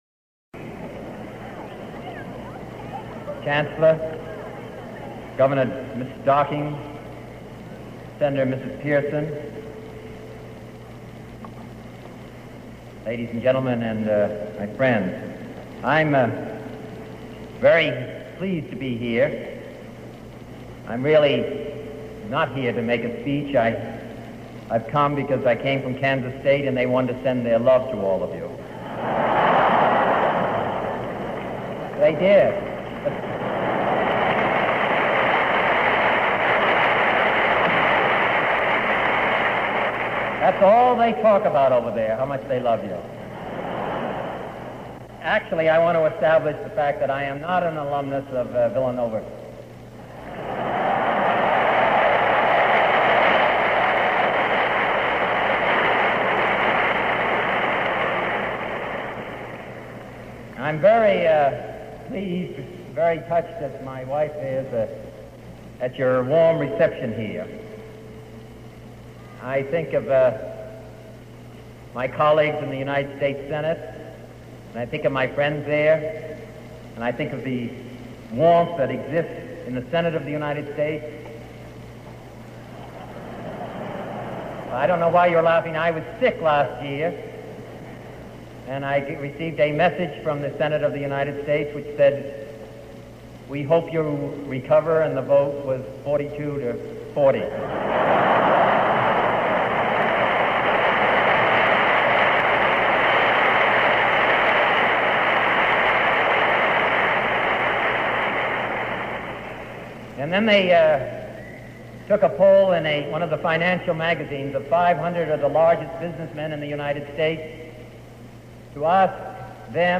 Robert-F-Kennedy-University-of-Kansas-Address.mp3